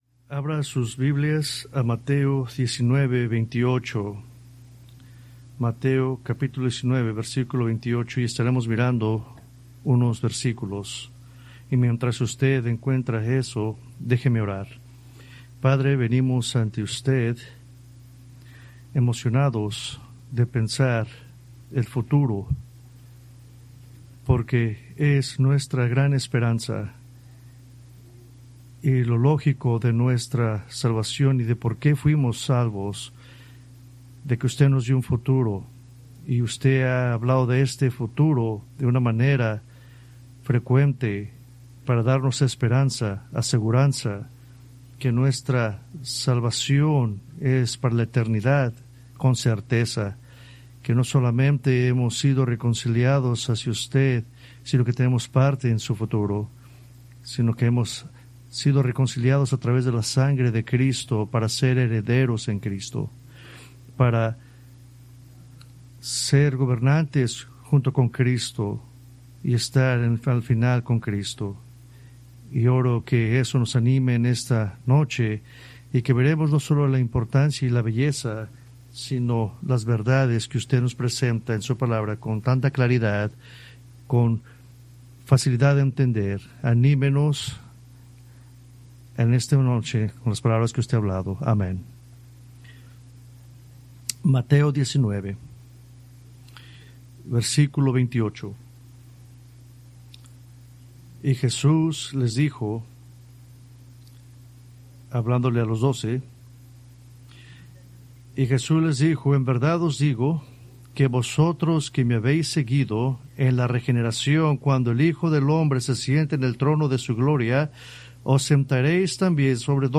Preached September 14, 2025 from Escrituras seleccionadas